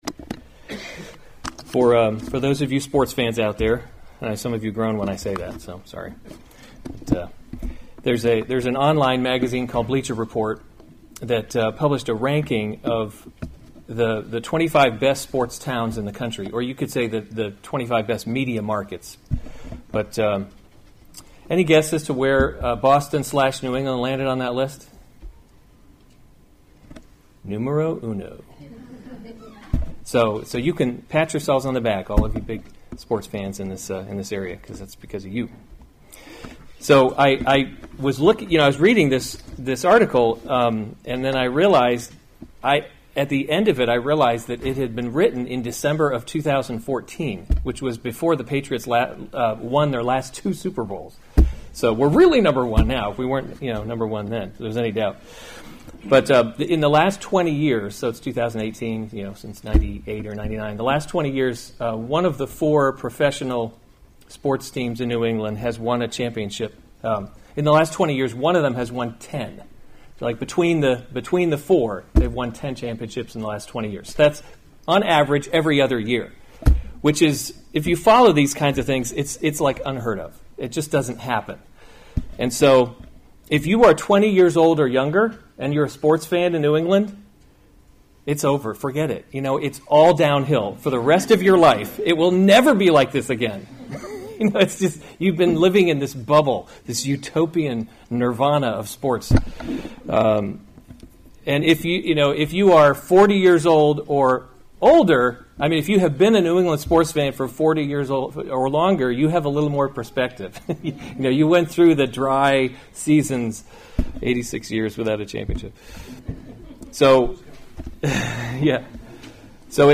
September 29, 2018 1 Kings – Leadership in a Broken World series Weekly Sunday Service Save/Download this sermon 1 Kings 4 Other sermons from 1 Kings Solomon’s Officials 4:1 King Solomon […]